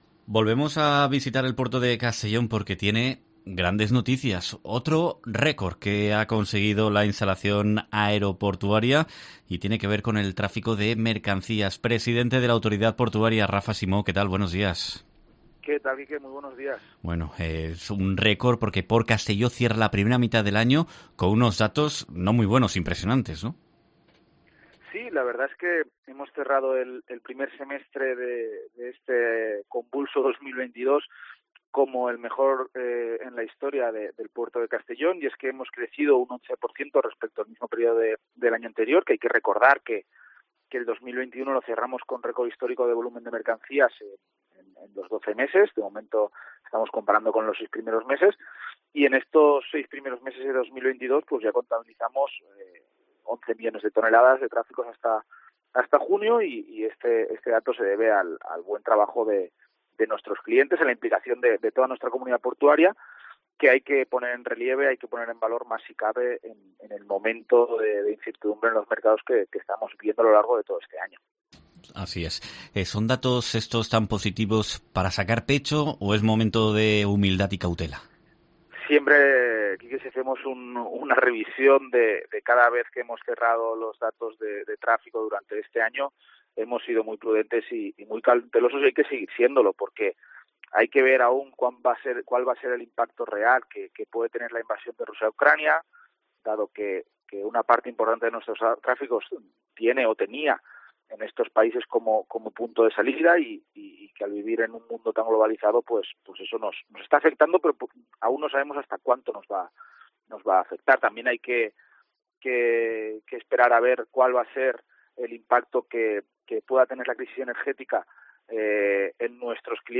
Entrevista
Buen arranque de año para PortCastelló, aunque con incertidumbre para el futuro, según explica en COPE el presidente de la Autoridad Portuaria, Rafa Simó